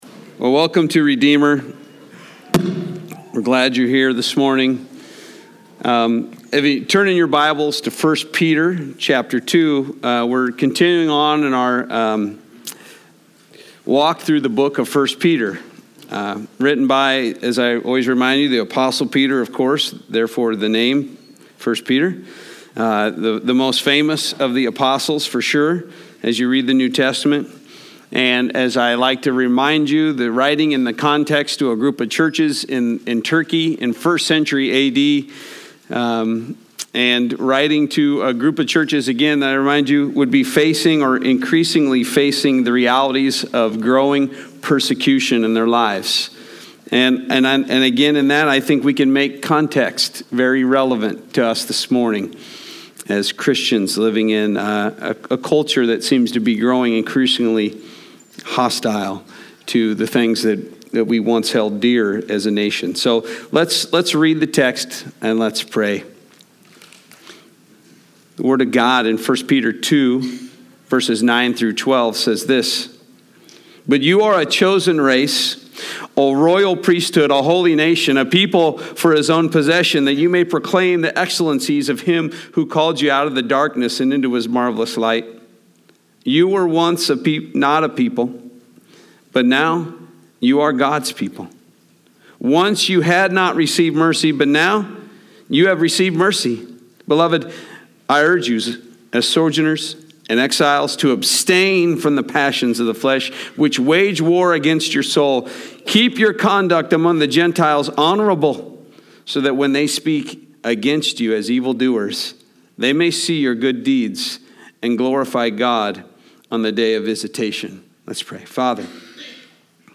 Sunday Morning 1 Peter - Living as a Sojourner in a Strange Land